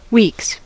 weeks: Wikimedia Commons US English Pronunciations
En-us-weeks.WAV